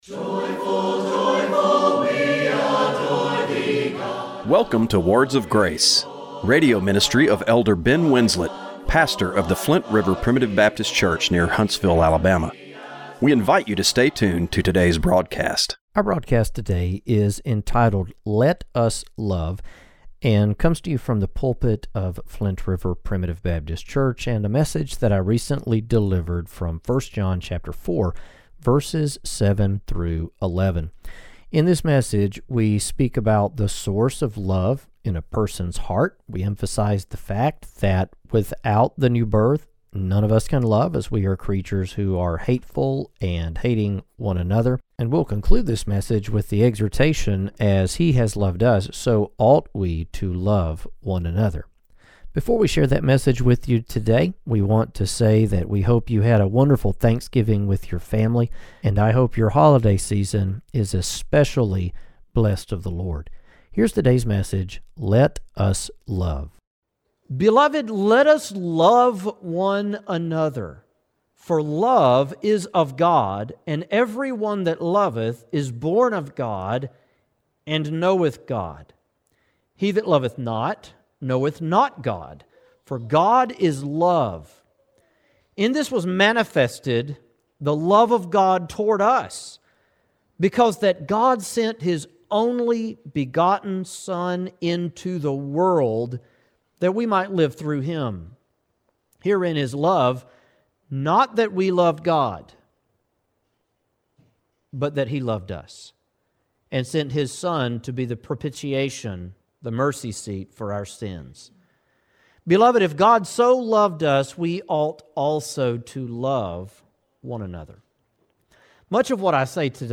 Radio broadcast for December 1, 2024.